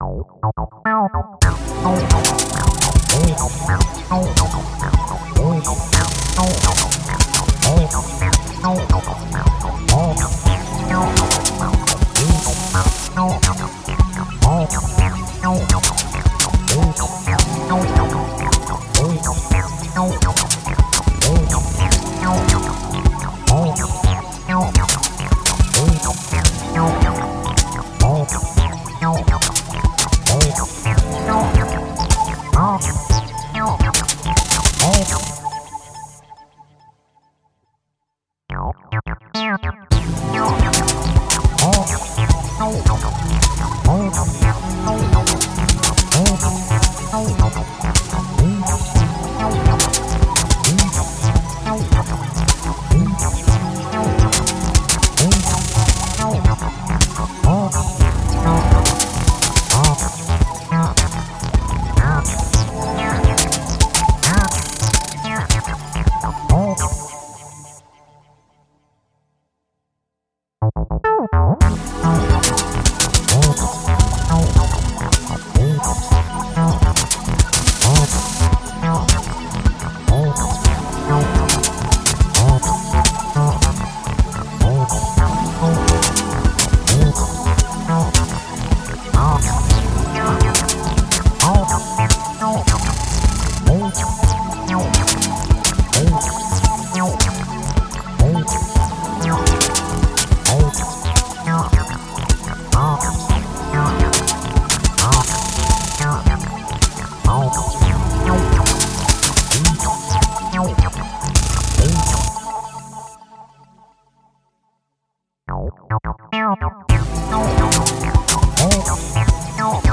Composed electronic music contrasted with ambient nature.
Tagged as: Electronica, Other